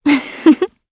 Ripine-Vox_Happy2_jp.wav